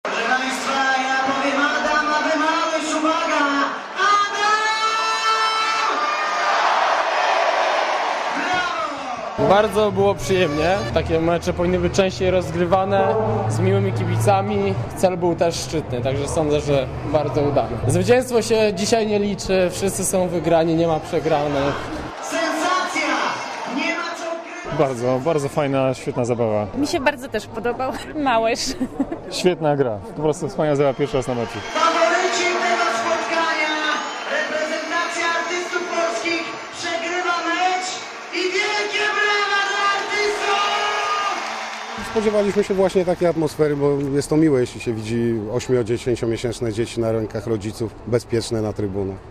Komentarz audio (390Kb)